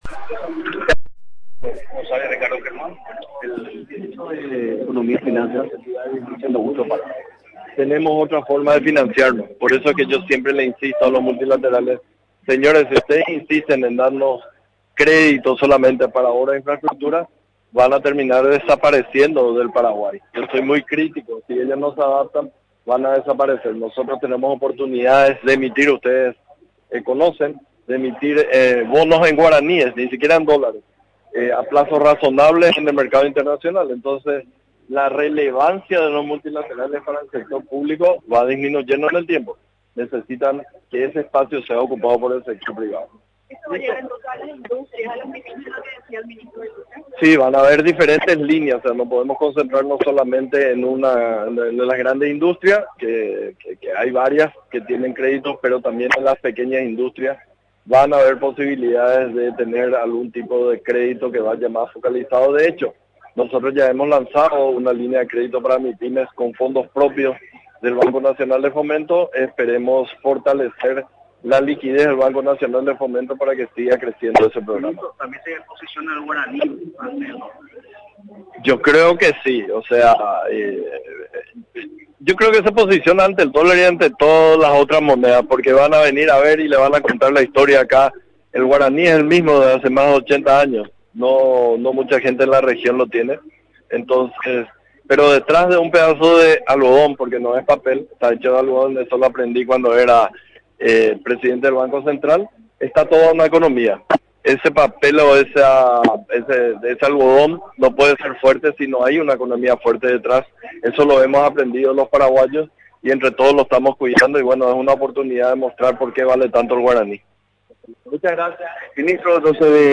El ministro de Economía y Finanzas, Carlos Fernández Valdovinos, destacó en la sede de la Conmebol, donde se desarrolla la Asamblea del Banco Interamericano de Desarrollo, que se trata de una oportunidad para mostrar a los visitantes porque está fuerte la moneda nacional, el guaraní.